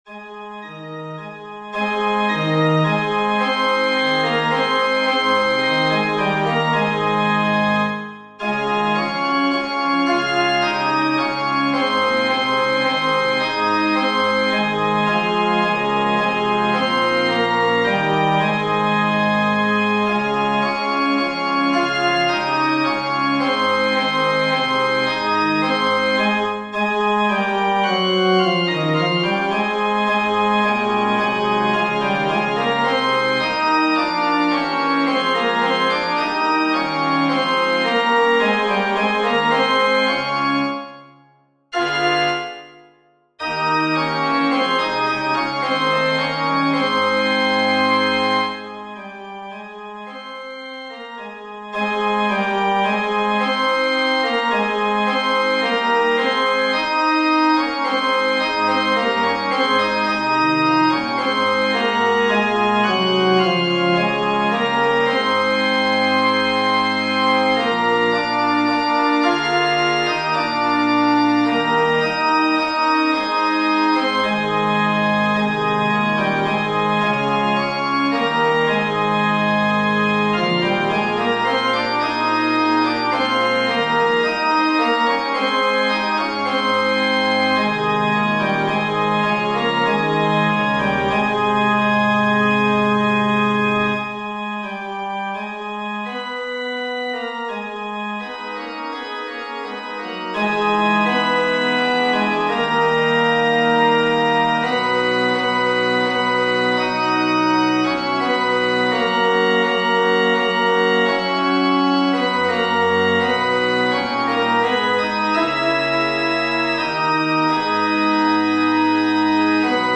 FF:VH_15b Collegium musicum - mužský sbor, FF:HV_15b Collegium musicum - mužský sbor